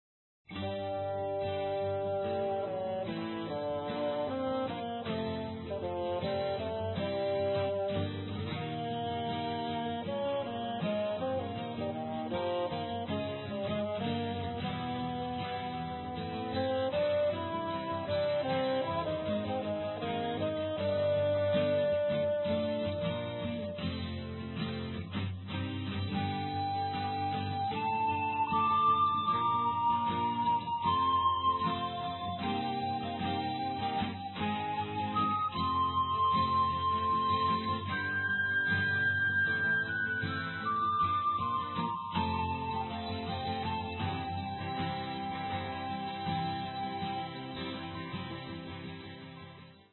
Mono, 0:49, 16 Khz, (file size: 98 Kb).